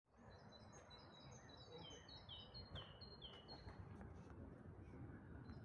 Im Volkspark Friedrichshain
Kohlmeise in Berlin
Kohlmeise.mp3